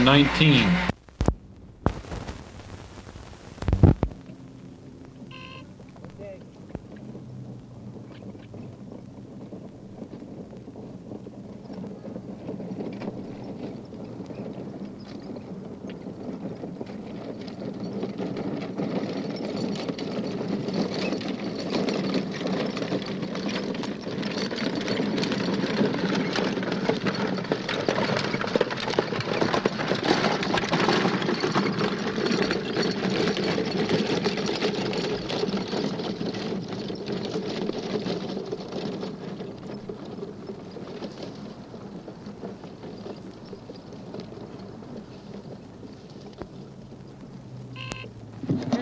古老的马车 " G5219 驶过来的马车
描述：Buckboard在坚硬的地面上小跑。以距离开始和结束。金属叮当声，一些马声。
我已将它们数字化以便保存，但它们尚未恢复并且有一些噪音。